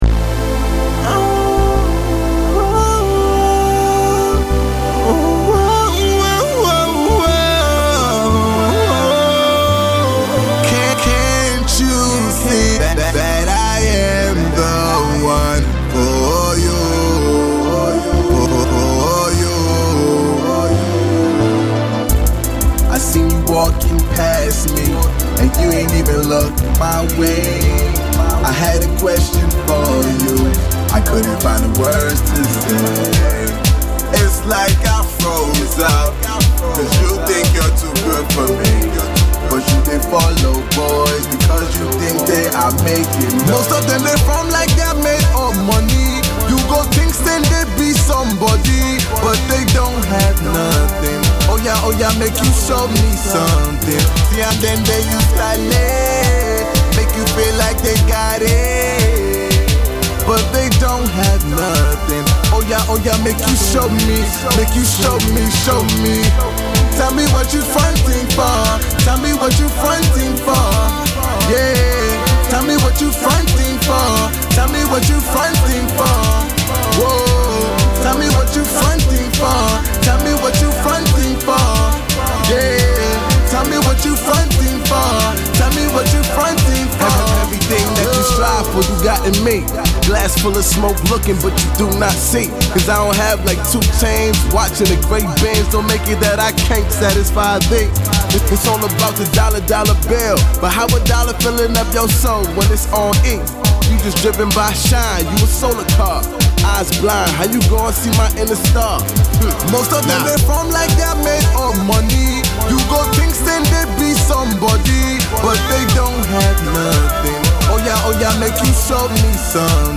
more laid back plagued by auto-tune